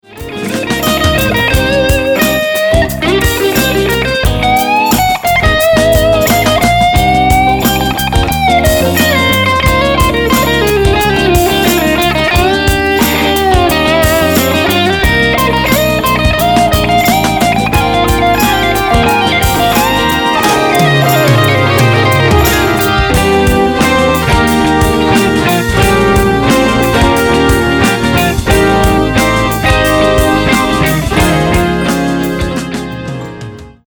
All Guitars, Keyboards, Synthesizers and Percusion
Drums on Track 8